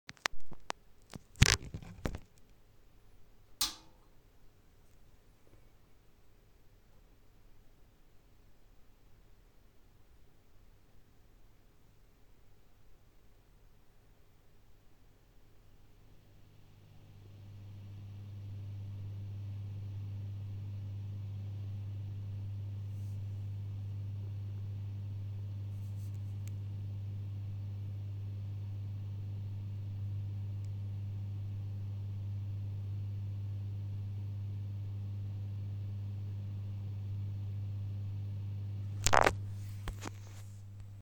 PRS MT 15 - tief-frequentes, eher leises Brummgeräusch
Brummen bleibt leise und tieffrequent, ist also weiterhin wahrnehmbar.
Anbei habe ich noch eine Aufnahme mit dem Handy gemacht. In der Aufnahme hört ihr den Amp beim Einschalten und aufwärmen, Standby bleibt aus. Im Vergleich zu der Aufnahme ist das Brummen im Raum deutlich besser wahrzunehmen als in dieser Handyaufnahme.